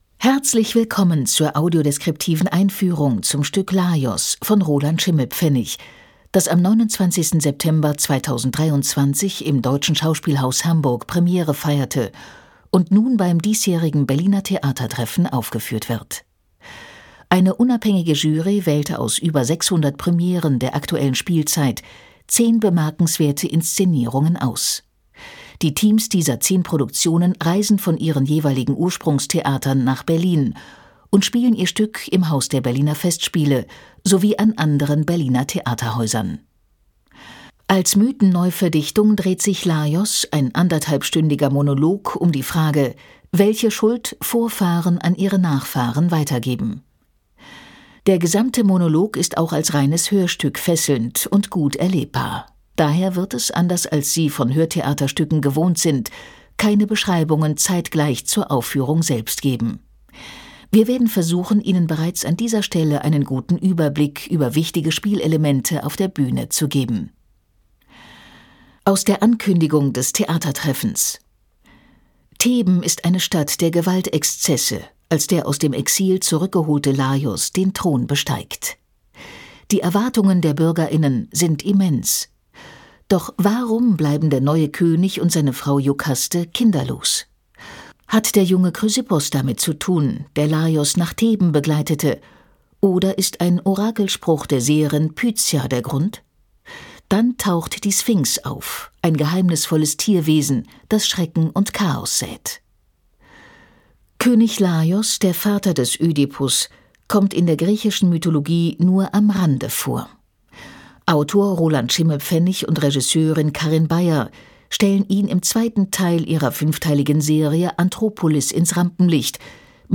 Audiodeskription „Laios“
tt24_audiodeskription_laios.mp3